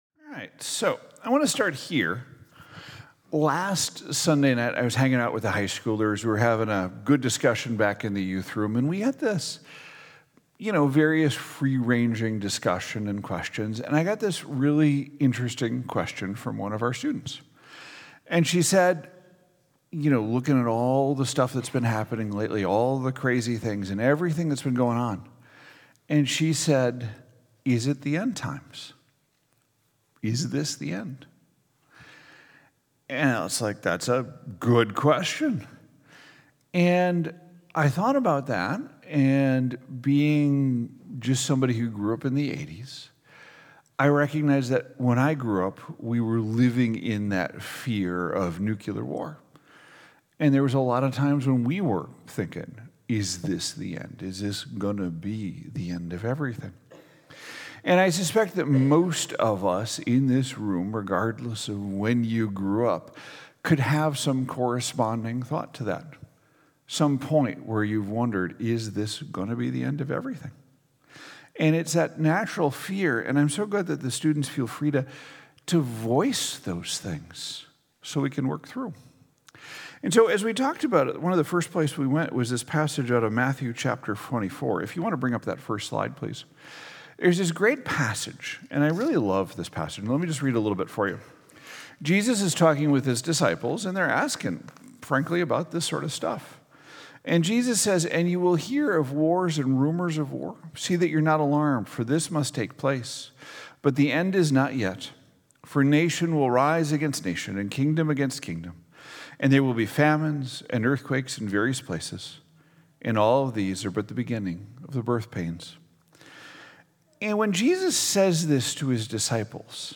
Sermon Series: Who Are We?